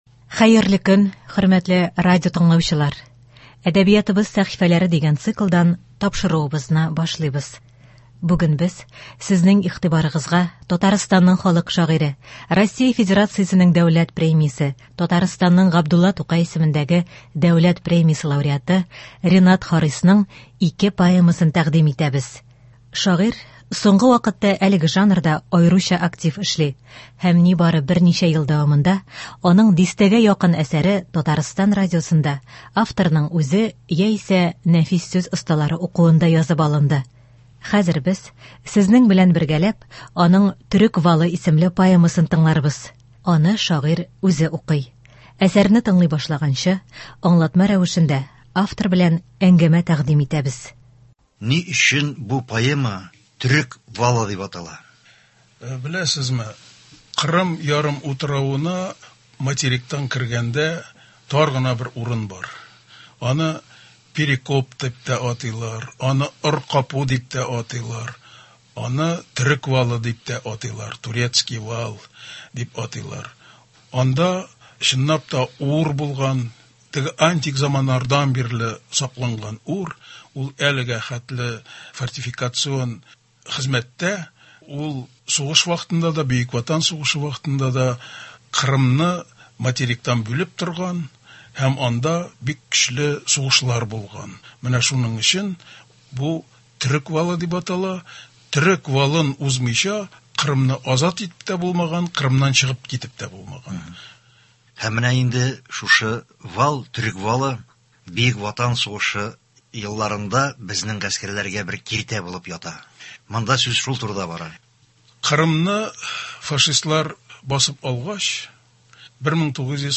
Хәзер сез, сезнең белән бергәләп, аның “Төрек валы” һәм “Гармунчы” исемле поэмаларын тыңларбыз. Аны шагыйрь үзе укый. Әсәрне тыңлый башлаганчы, аңлатма рәвешендә, автор белән әңгәмә тәкъдим ителә.